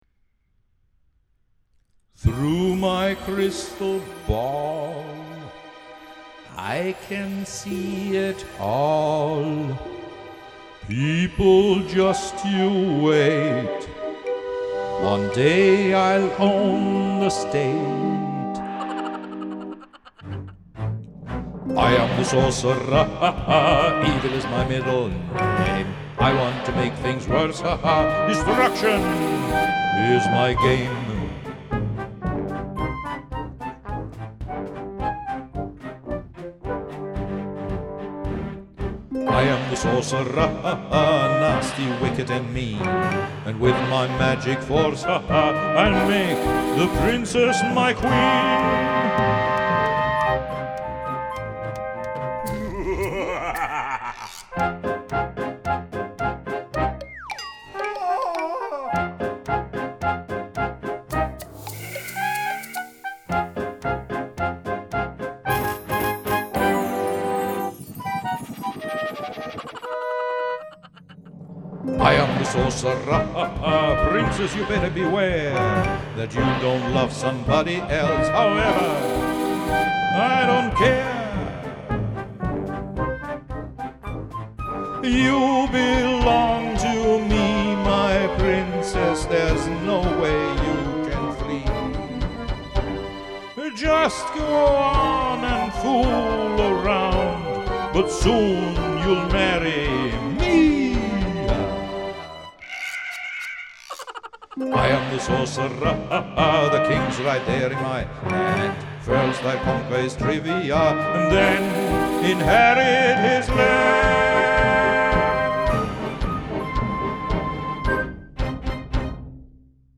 Chaos beim Schlußakkord, alle sinken erschöpft zu Boden.